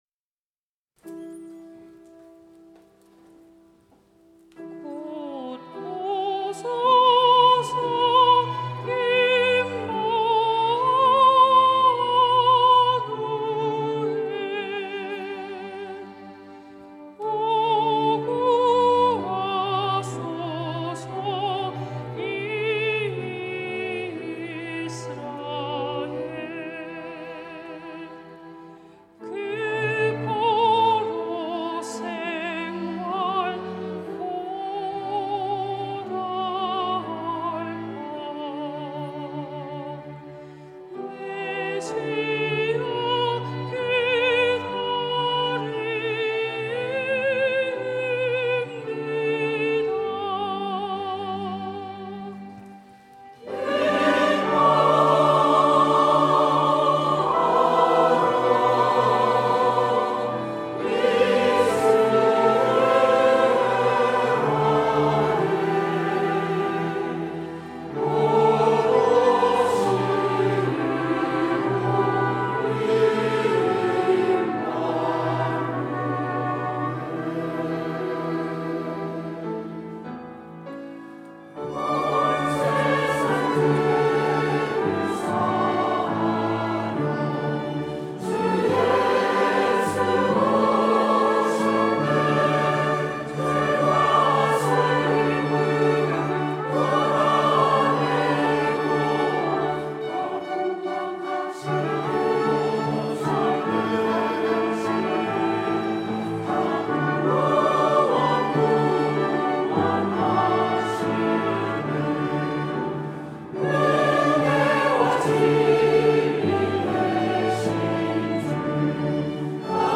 2부 찬양대